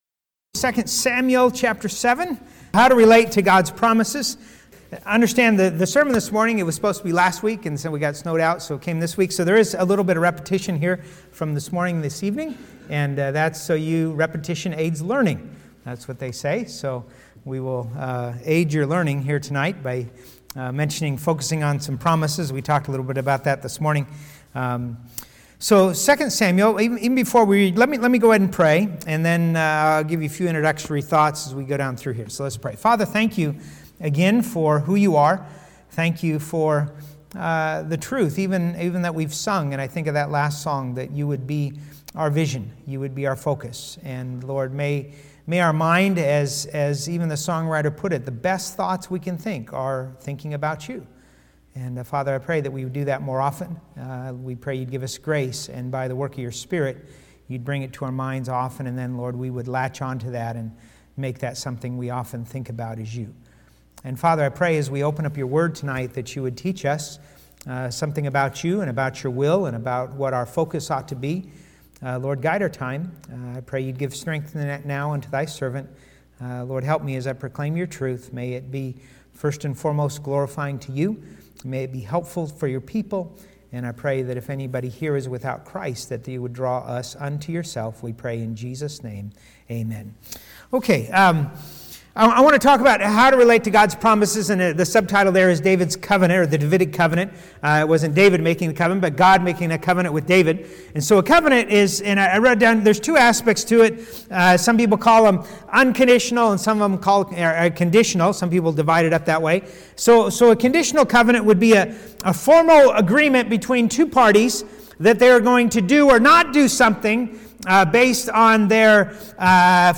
Evening Service – Galilee Baptist Church